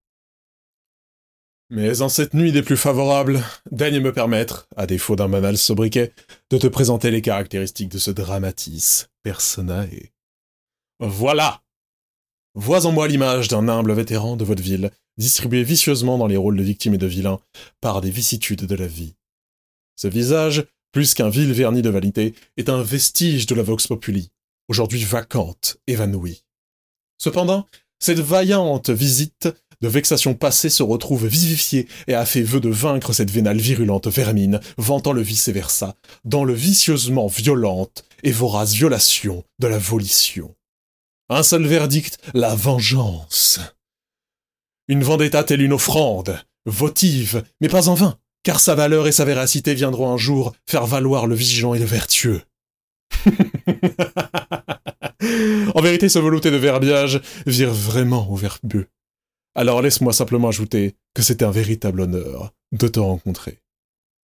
Bandes-son
27 - 35 ans - Ténor